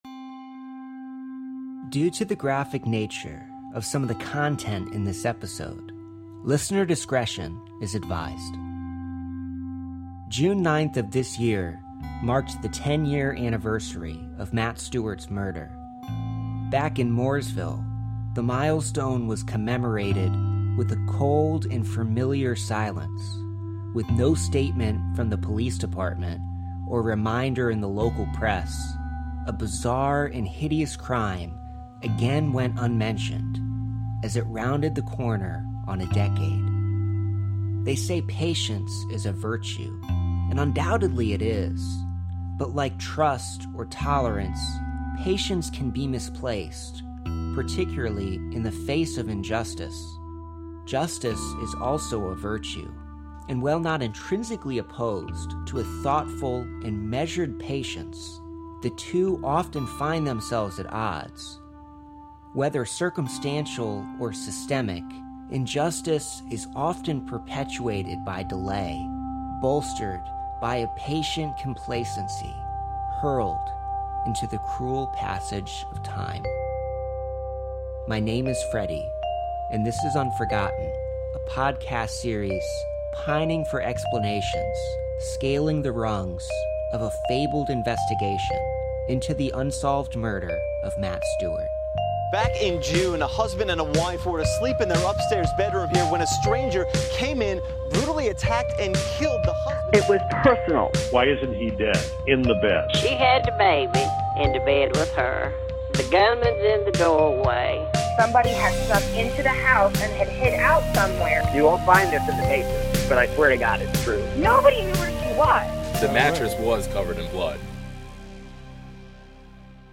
The series dives into the strange facts of the crime and features exclusive interviews with the victim's friends, family, and neighbors, as well as commentary from experts.